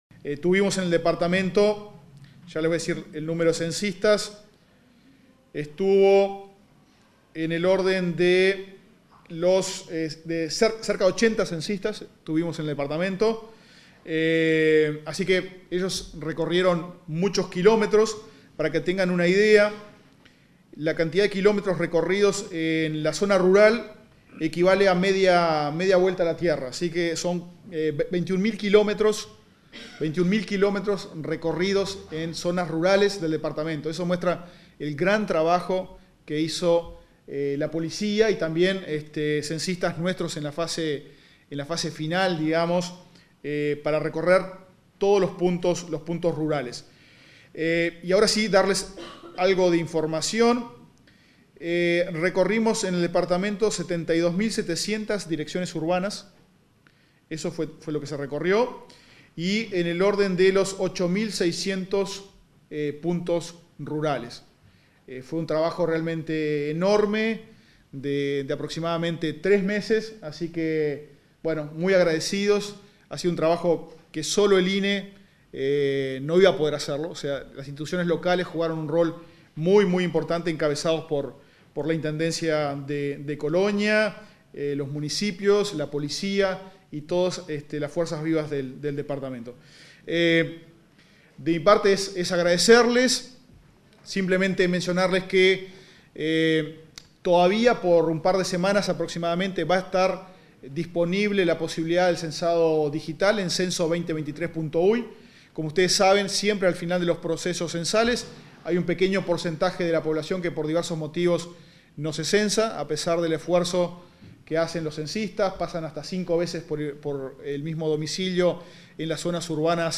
Palabras del director técnico del INE, Diego Aboal